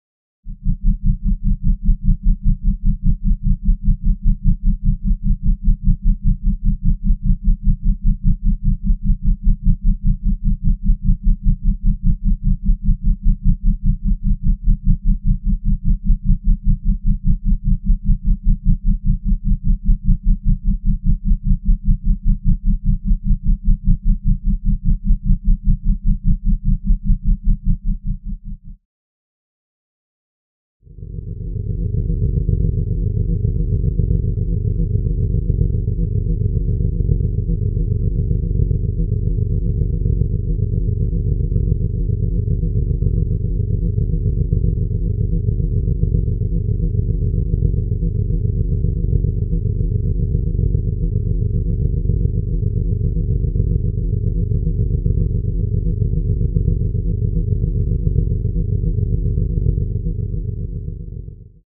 Звуки низкой частоты
Глубокие басы, инфразвук и резонансные волны могут вызывать неоднозначные ощущения — от легкого дискомфорта до сильной усталости.